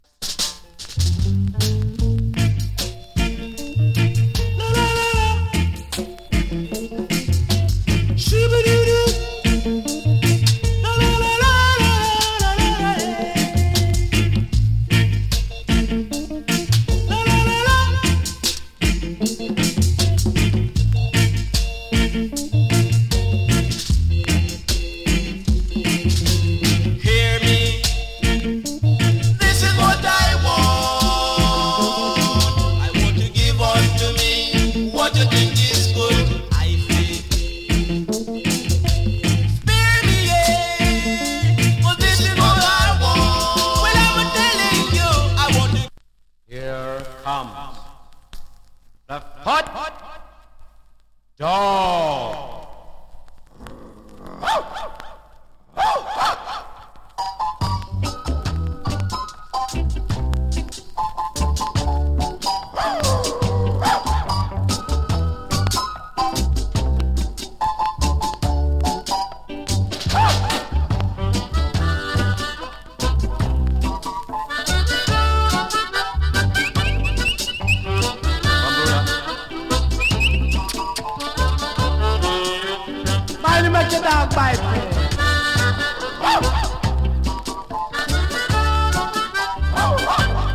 ＊スリキズ少し有り。チリ、パチノイズ少し有り。
RIDDIM FINE INST